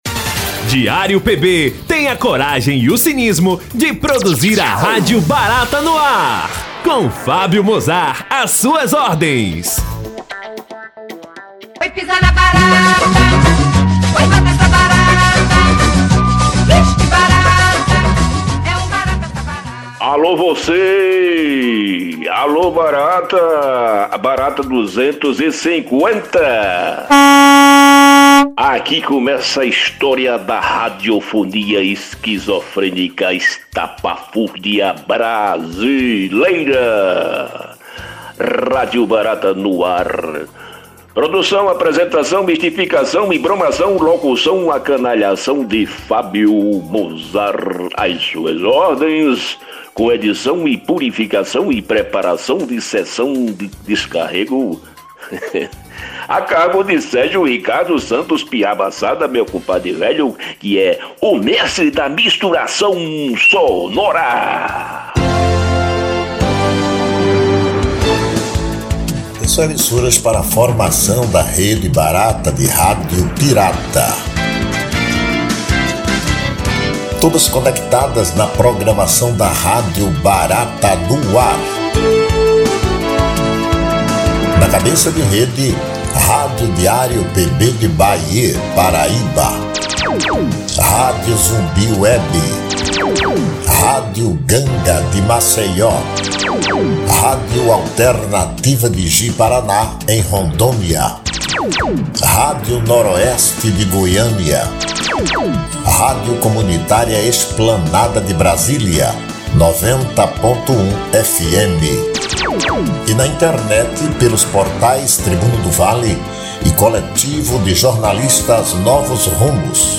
O objetivo do programa é abordar assuntos do cotidiano, comentar de maneira bem humorada, os acontecimentos da semana, de forma irreverente e leve, sempre com boas piadas.